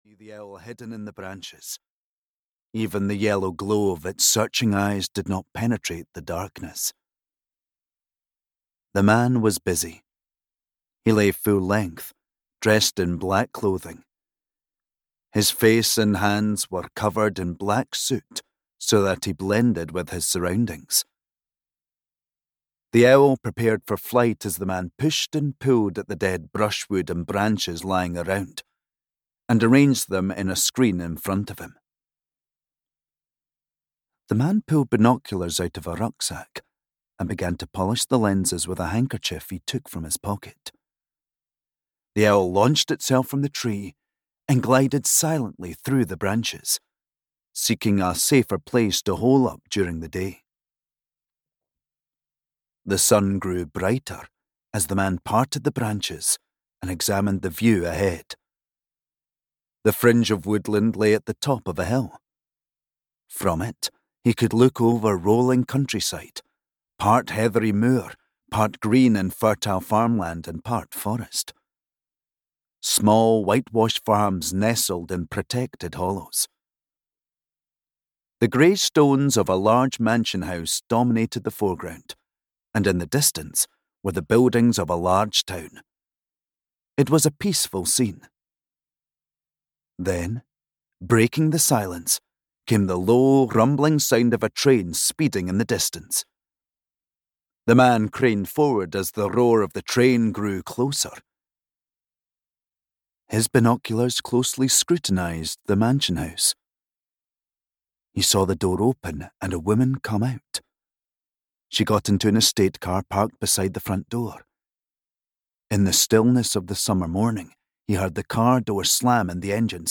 Audio knihaLight on Dumyat (EN)
Ukázka z knihy